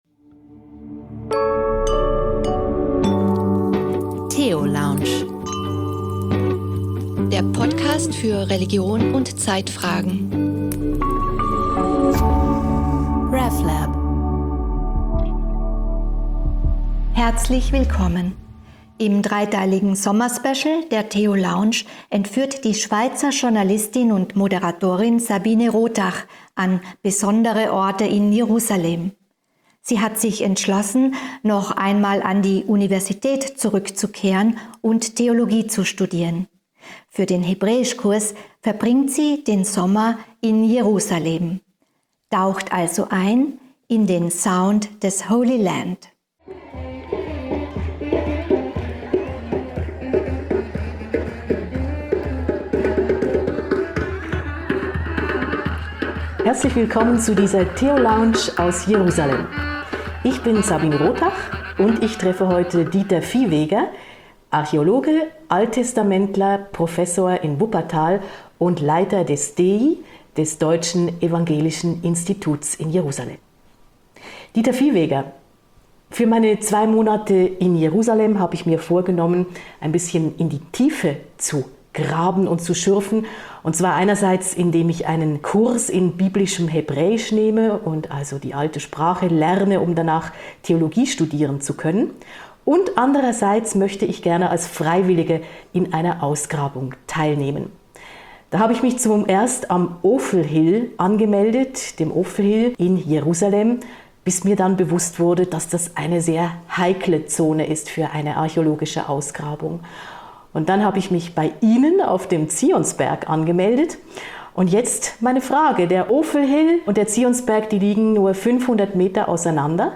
Wir reden nicht über Kultur, Spiritualität oder Religion, sondern aus den jeweils prägenden kulturellen und spirituellen Erfahrungen heraus. Deep Talk und Shared Knowledge jenseits von Glaube oder Unglaube.